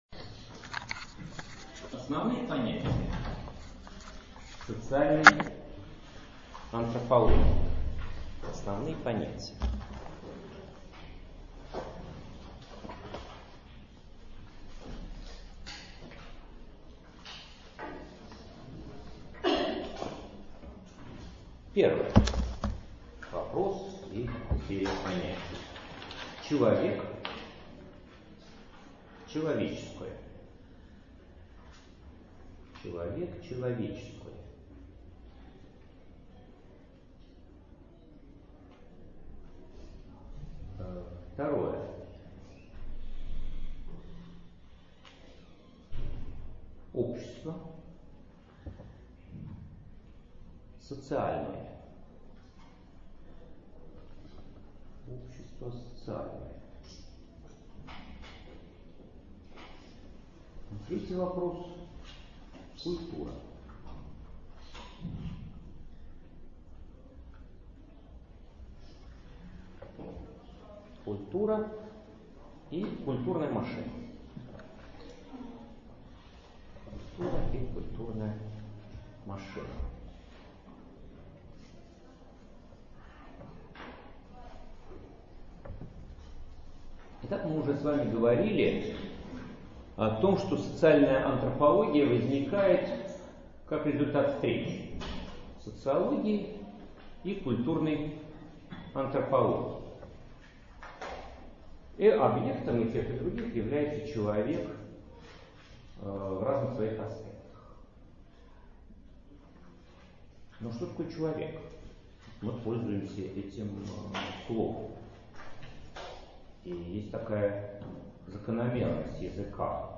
Аудиокнига Основные понятия социальной антропологии | Библиотека аудиокниг